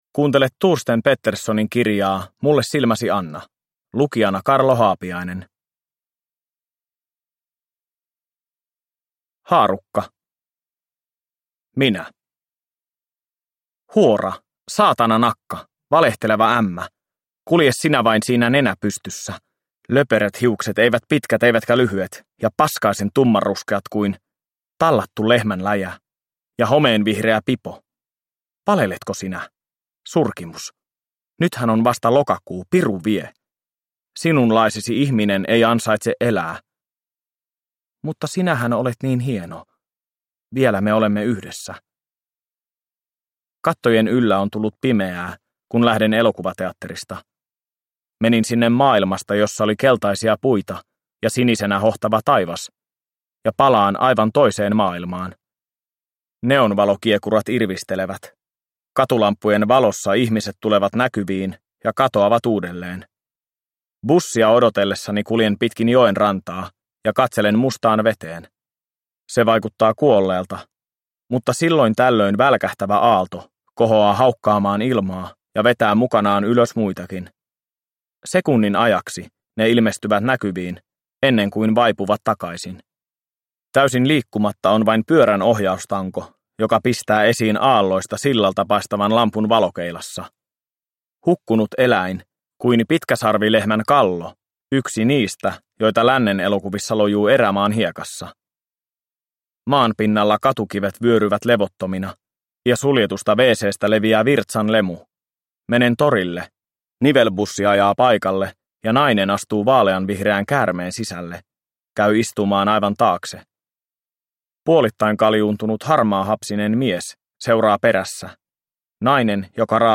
Mulle silmäsi anna – Ljudbok – Laddas ner